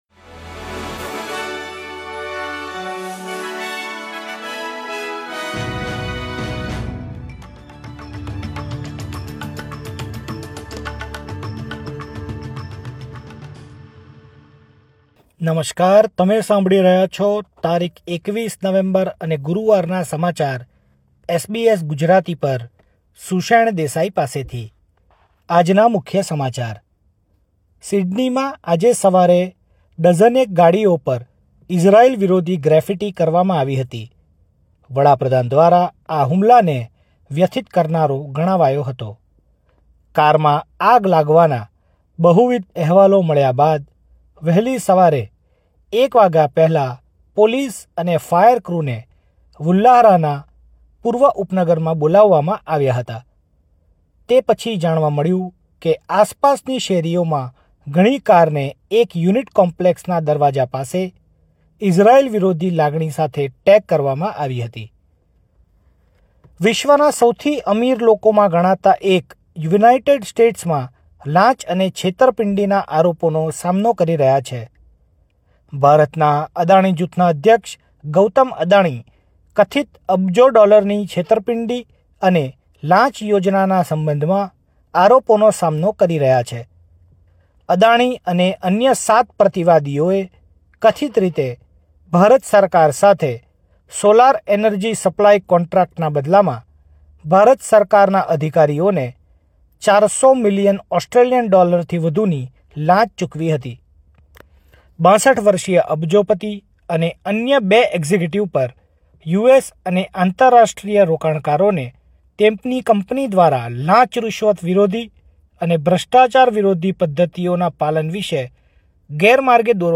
SBS Gujarati News Bulletin 21 November 2024